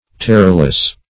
Terrorless \Ter"ror*less\, a.